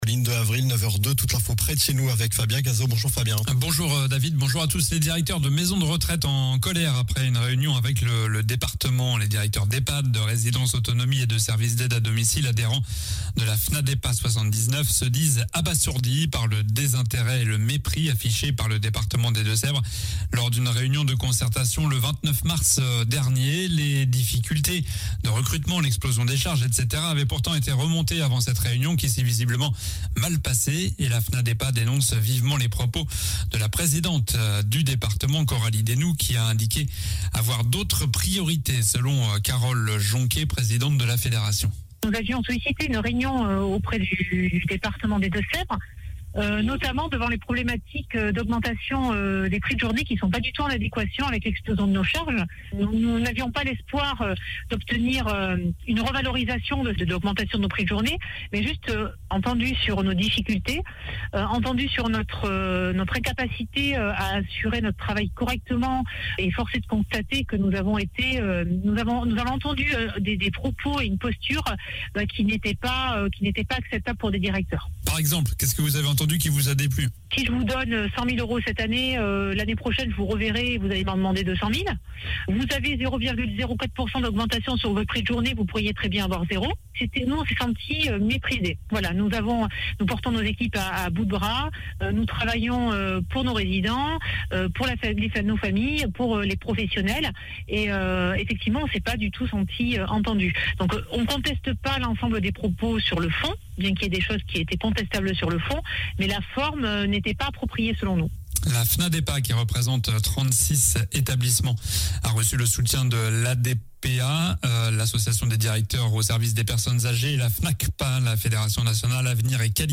Journal du samedi 02 avril (midi)